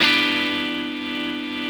ChordG.wav